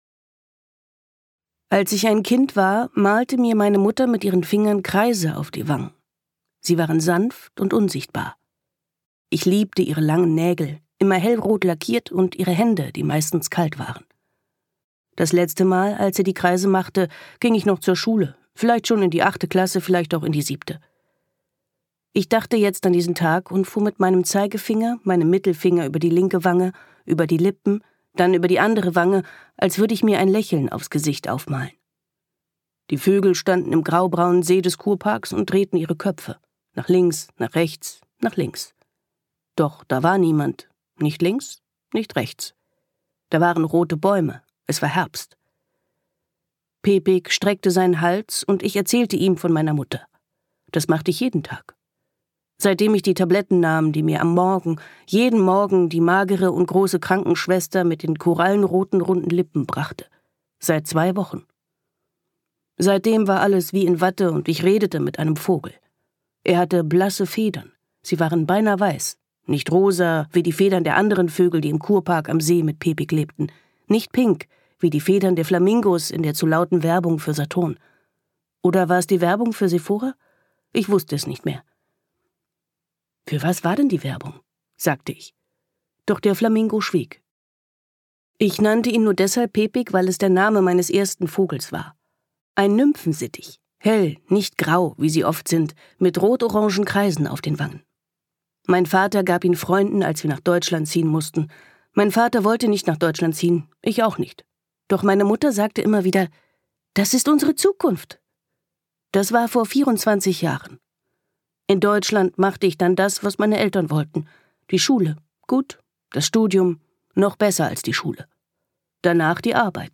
Frauen im Sanatorium - Anna Prizkau | argon hörbuch
Gekürzt Autorisierte, d.h. von Autor:innen und / oder Verlagen freigegebene, bearbeitete Fassung.